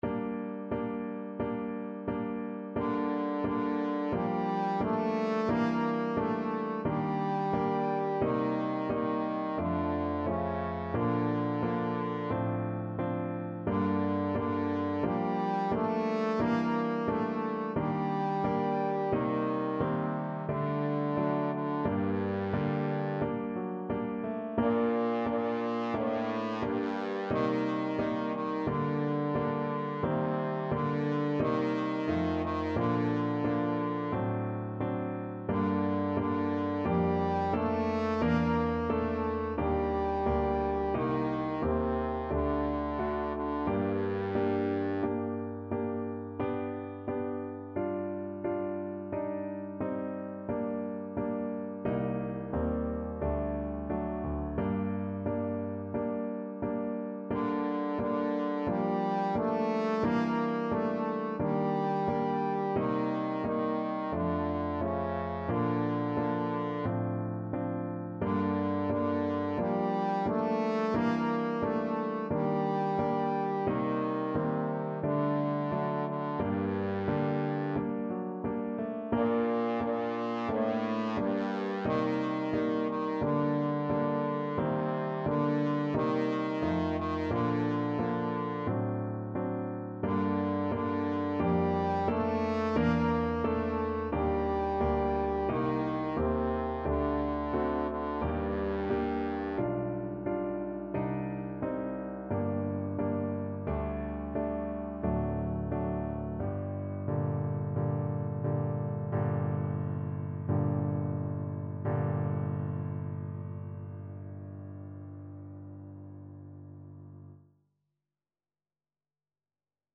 4/4 (View more 4/4 Music)
Andante =c.88
Classical (View more Classical Trombone Music)